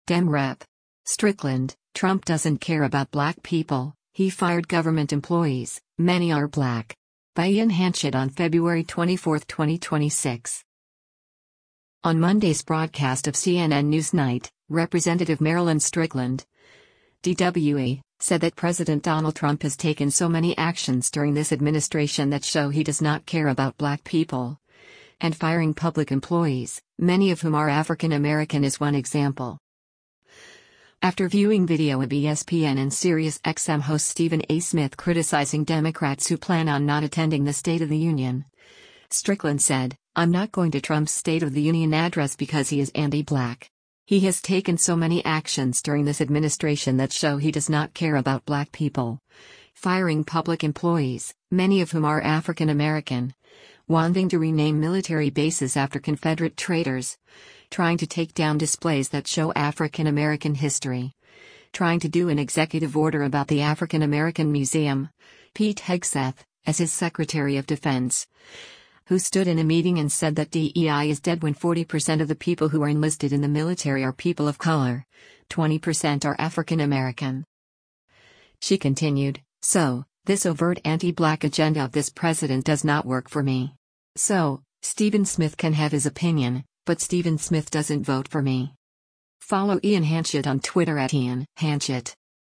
On Monday’s broadcast of “CNN NewsNight,” Rep. Marilyn Strickland (D-WA) said that President Donald Trump “has taken so many actions during this administration that show he does not care about black people,” and “firing public employees, many of whom are African American” is one example.